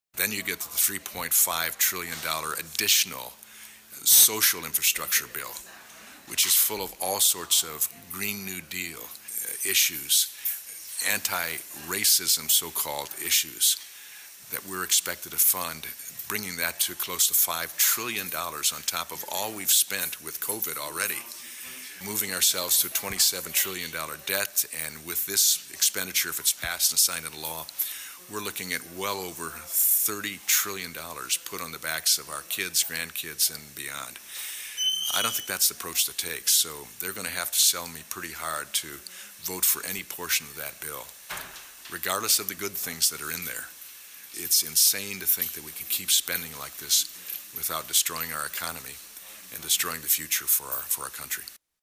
Congressman Walberg talked about the ‘social infrastructure bill’…